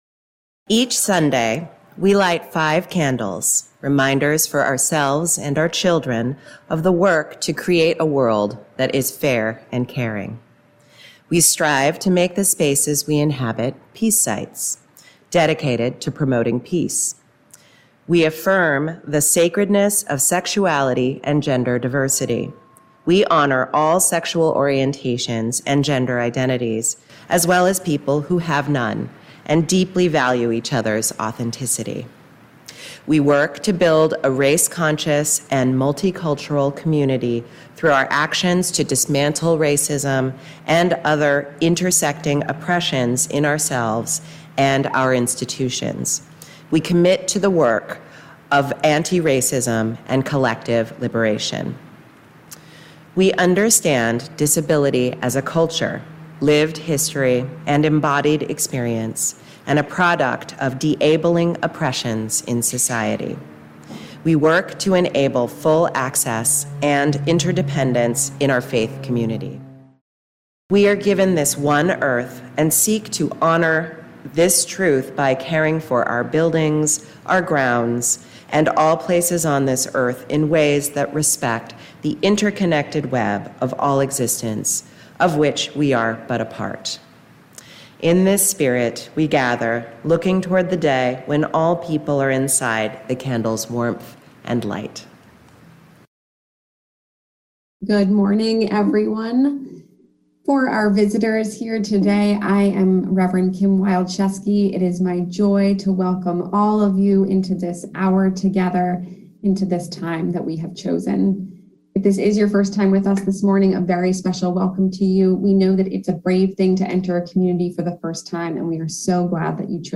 Sunday Service April 3, 2022